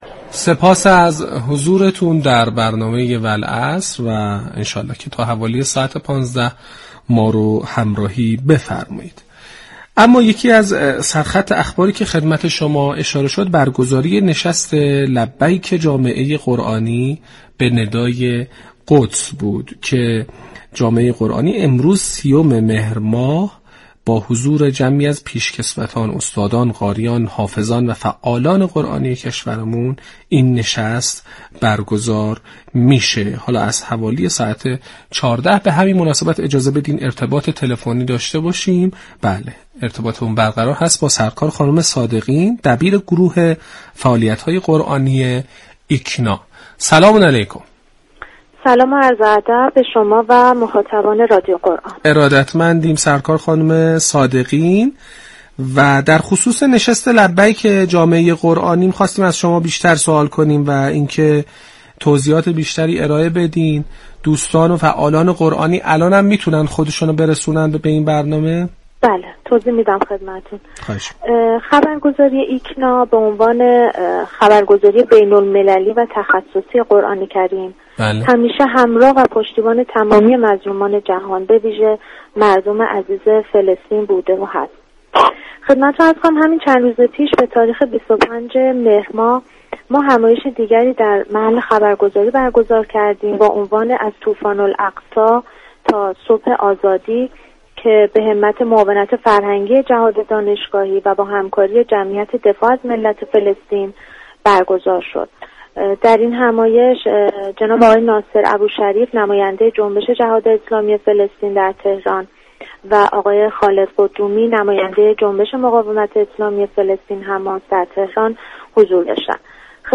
گفتنی است؛ جنگ عصرگاهی "والعصر" كه با رویكرد اطلاع رسانی یكشنبه تا چهارشنبه ی هر هفته بصورت زنده از رادیو قرآن پخش می شود.